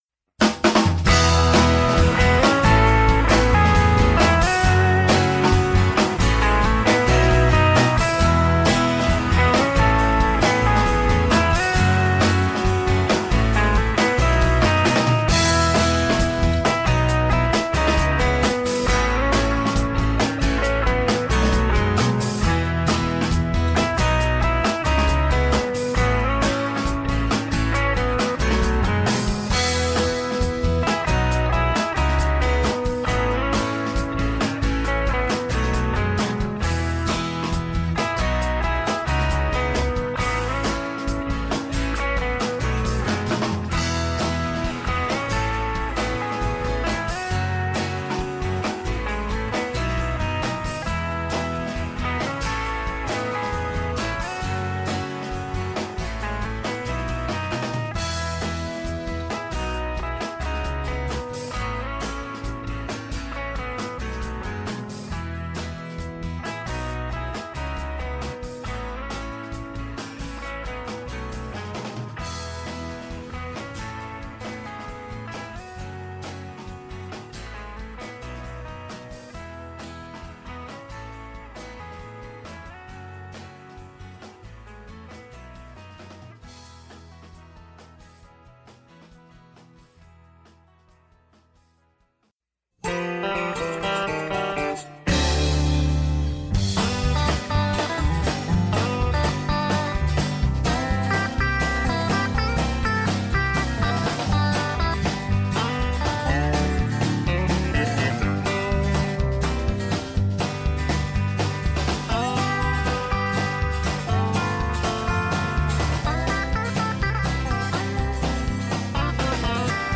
unique blend of latin, jazz and blues